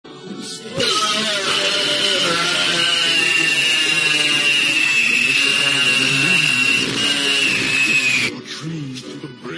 Play Loud Groaning Lol - SoundBoardGuy
loud-groaning-lol.mp3